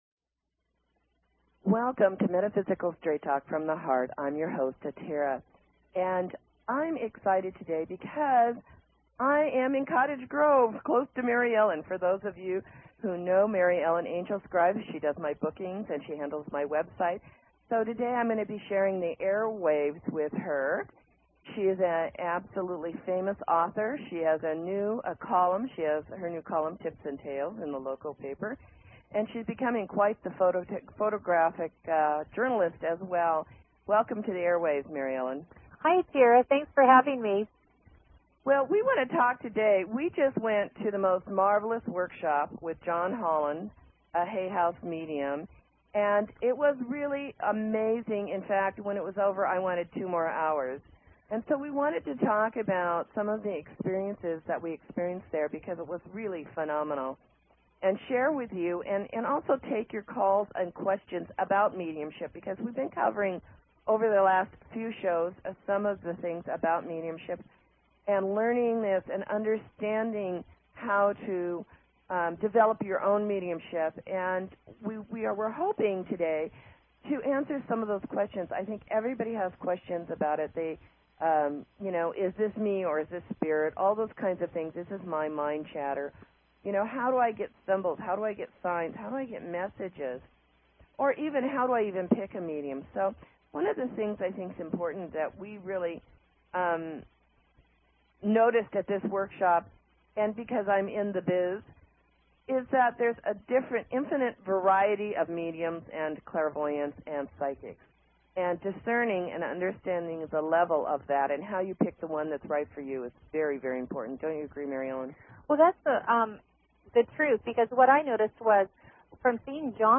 Talk Show Episode, Audio Podcast, Metaphysical_Straight_Talk and Courtesy of BBS Radio on , show guests , about , categorized as